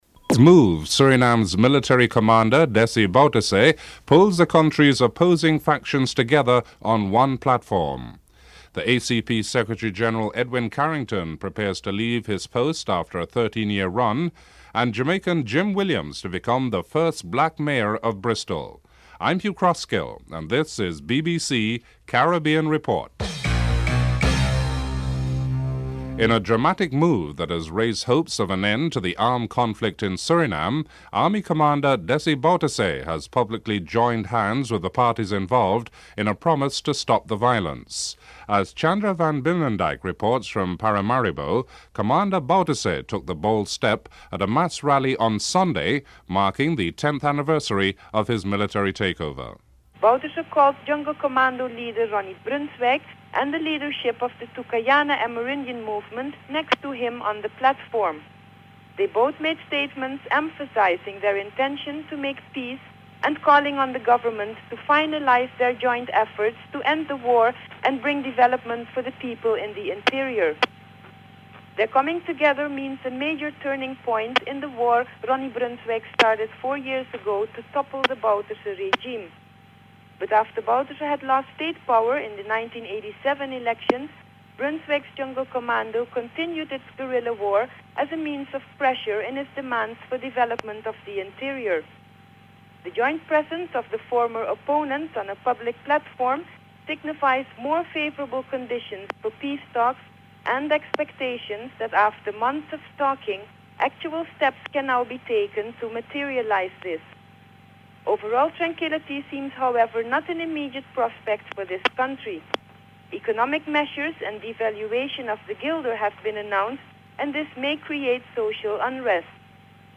1. Headlines (00:00 - 00:26)
4. Financial News.